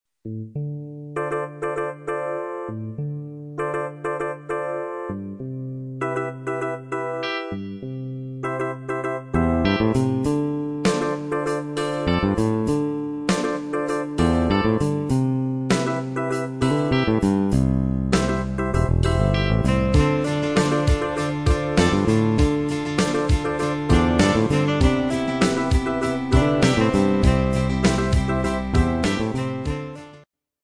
Pop
70s Rock